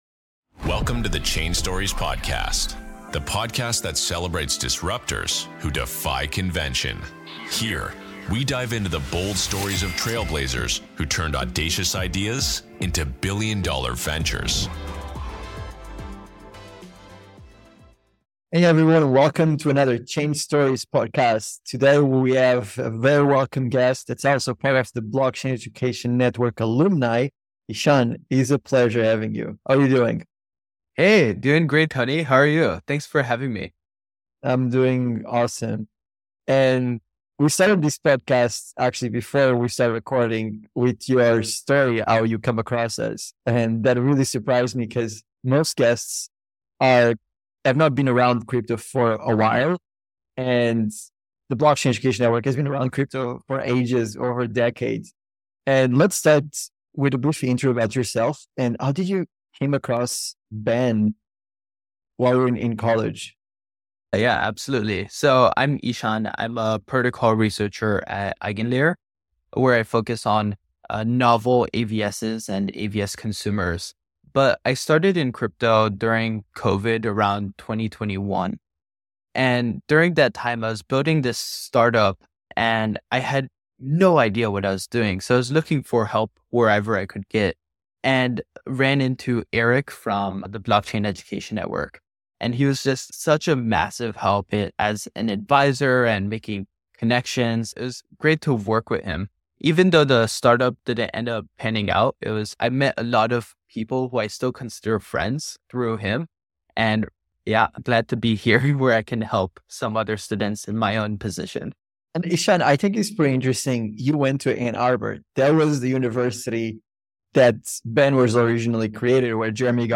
Why Crypto Is Worth the Risk - Interview with Eigenlayer